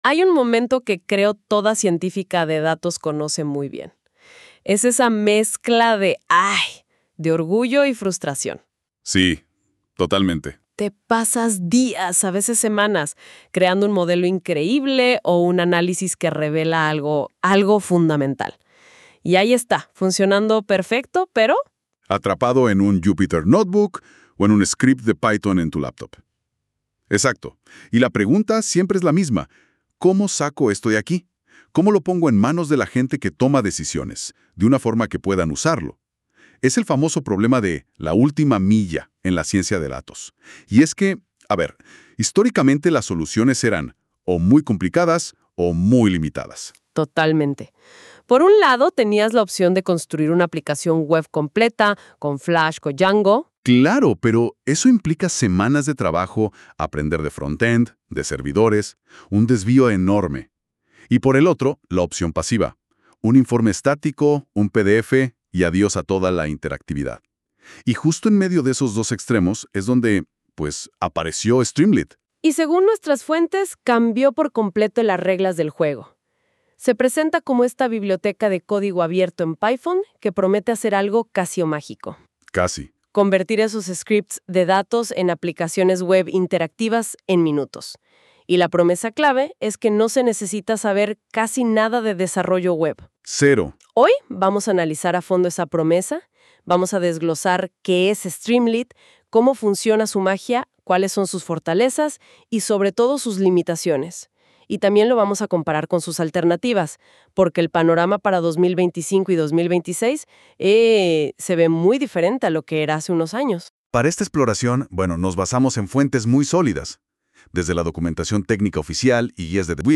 Podcast corto • 12–18 min Narrado por una experta en Python y analítica, para chicas jóvenes y adolescentes.